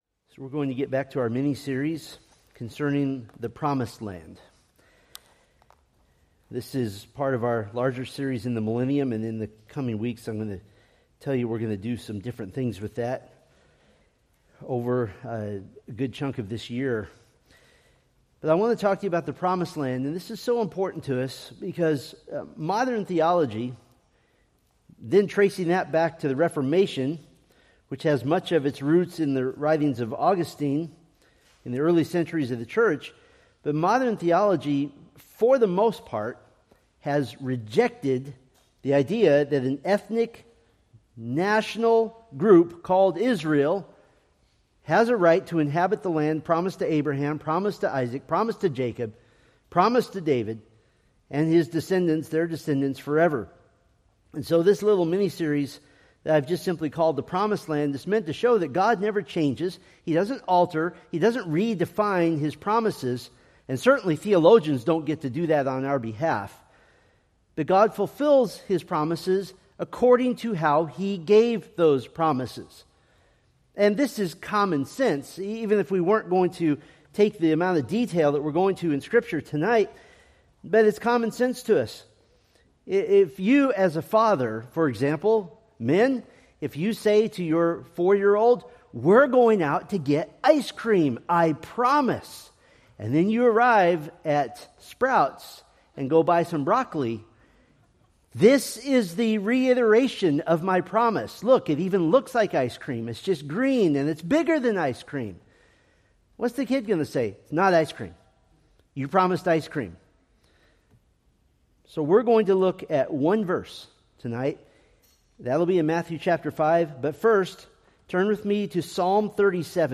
Preached March 22, 2026 from Selected Scriptures